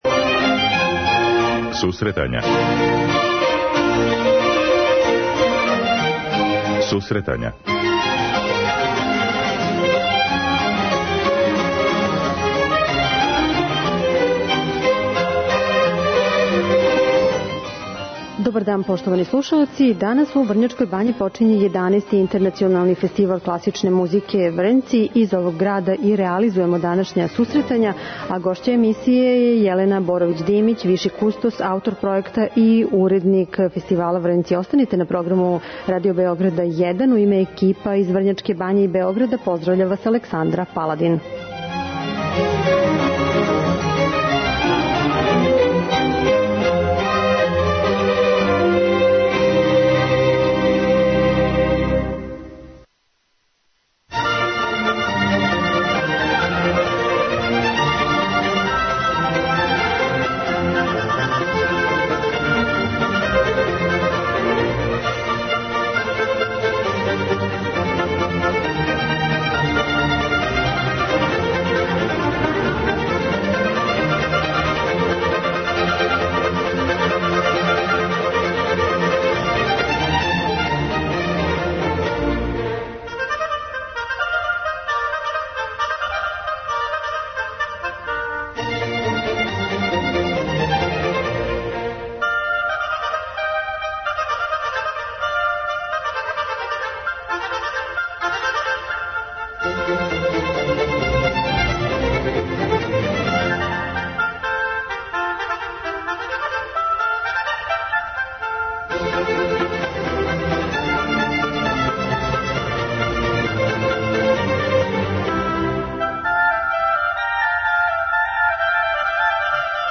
Емисију реалузују екипе Радио Београда 1 у Врњачкој бањи и Београду.